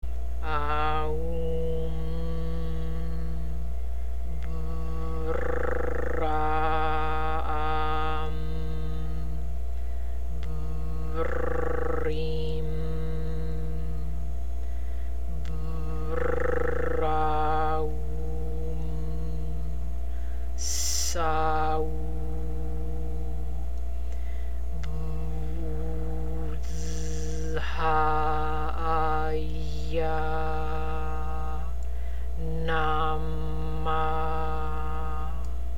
МАНТРА ЗА МЕРКУРИЙ:
AАА-УУУ-MMM ٠ Б-РР-AА-AА-MM ٠ Б-РР-ИИ-MM ٠ Б-РР-AА-УУ-MM ٠ ССА-УУУ ٠ БУУ-ДЗЗ-ХХА-А-ИЯА ٠ НАА-MAА
Артикулирайте "Р" правилно.
3 - Mercury Square Mantra.mp3